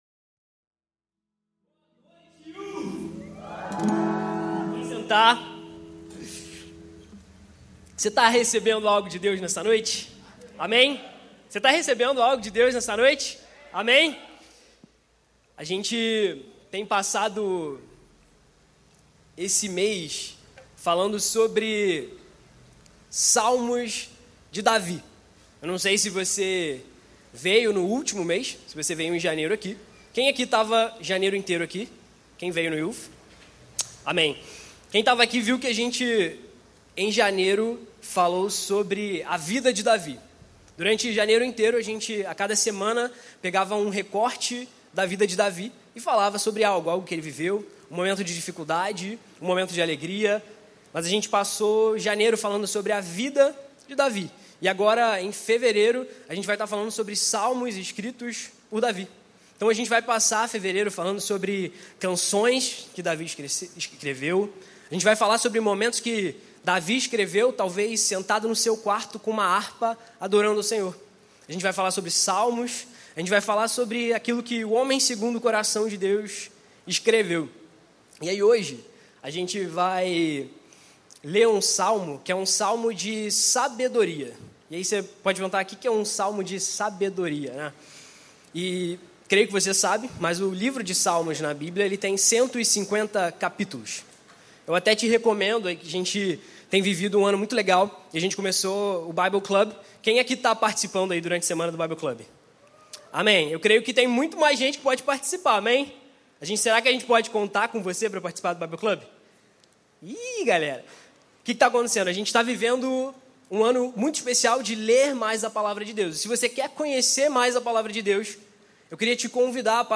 Mensagem
como parte da série Youth na Igreja Batista do Recreio.